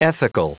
Prononciation audio / Fichier audio de ETHICAL en anglais
Prononciation du mot ethical en anglais (fichier audio)